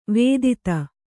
♪ vēdita